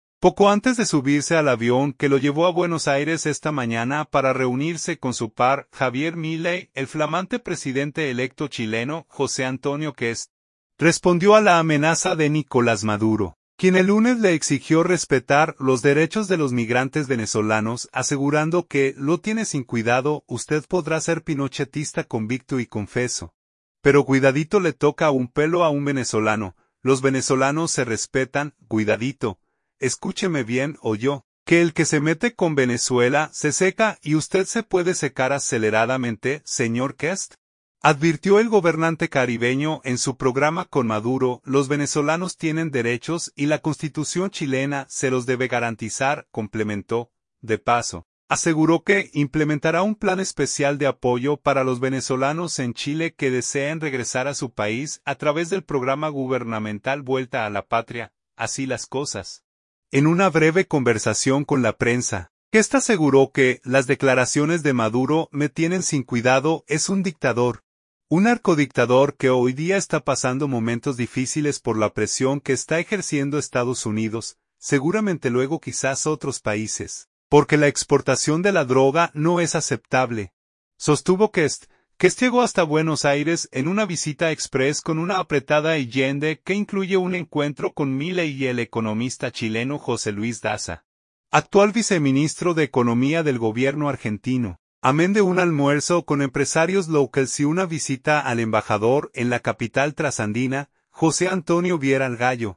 Así las cosas, en una breve conversación con la prensa, Kast aseguró que las declaraciones de Maduro “me tienen sin cuidado”.